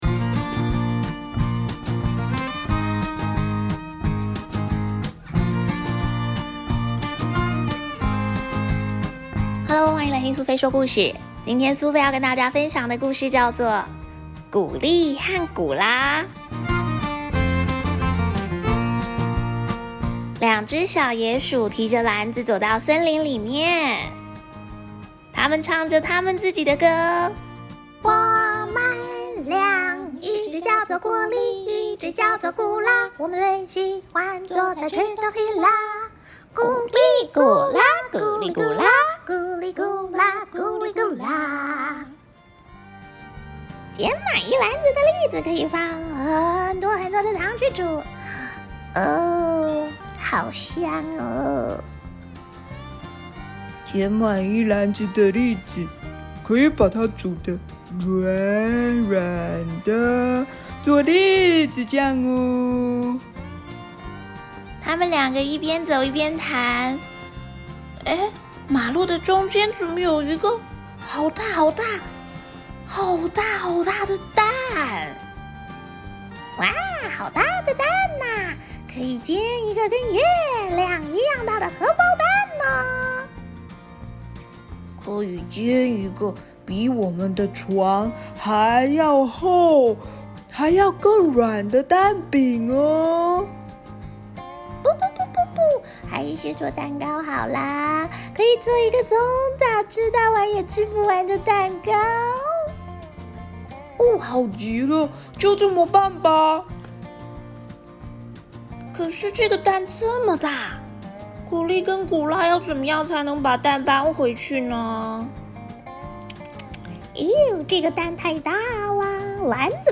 第一次用兩軌咪了一首可愛的小曲子 就送給古利和古拉當主題曲吧❤ 古利和古拉也算是歷久不衰的長青...